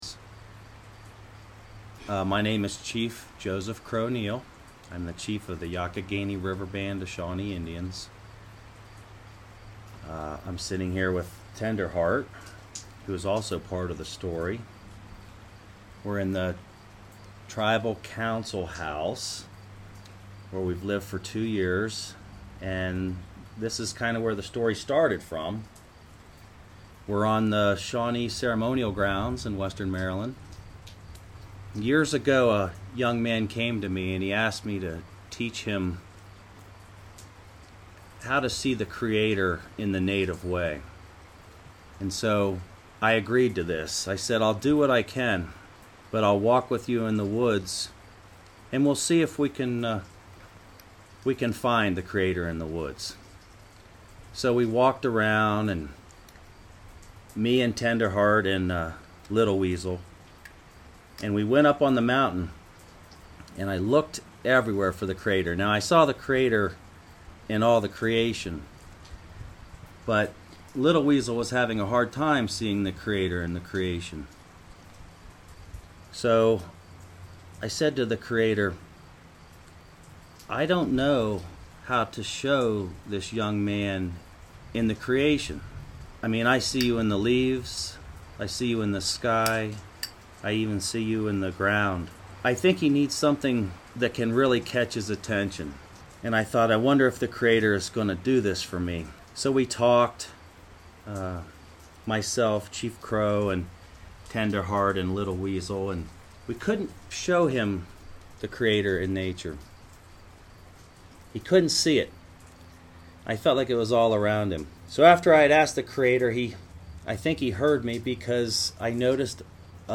Tree stories is a collection of audio stories that were recorded in Allegheny and Garrett counties over a summer and fall in Western Maryland.